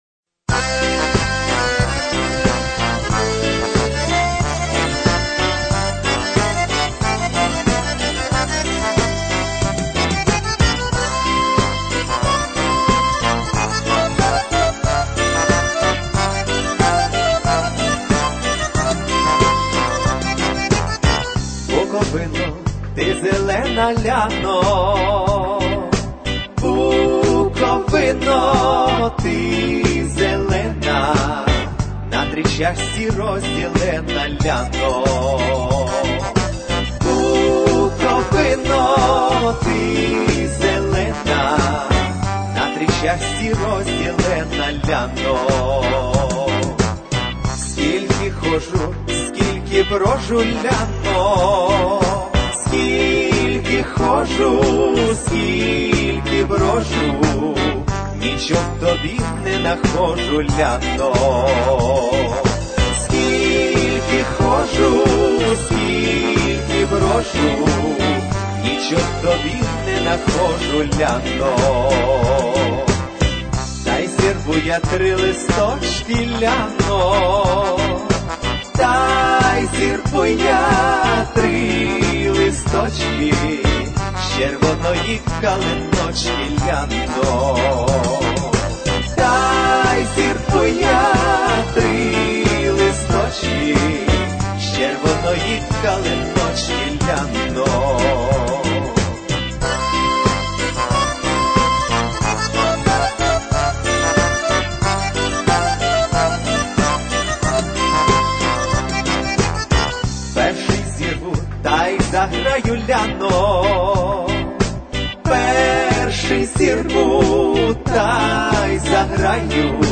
Всі мінусовки жанру Pop-Folk
Плюсовий запис